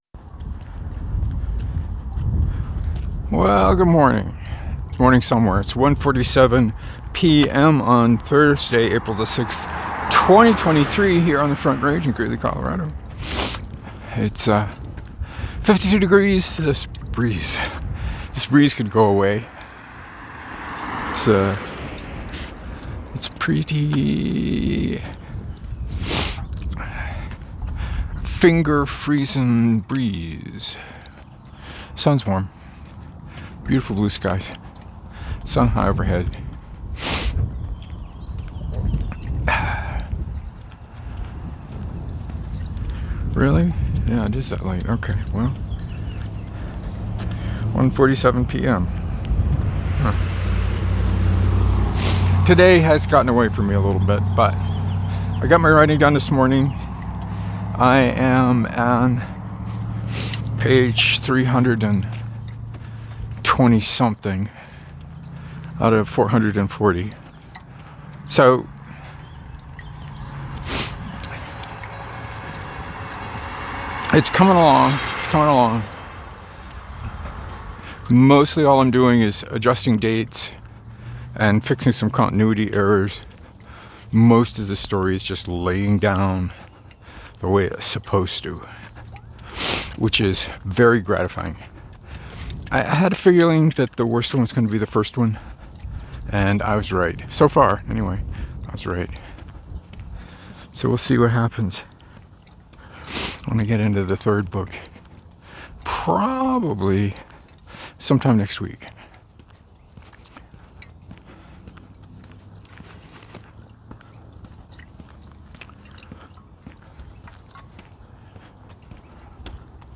Between the leaf blowers, ditch diggers, and trash trucks, the yappy dogs hardly stood a chance.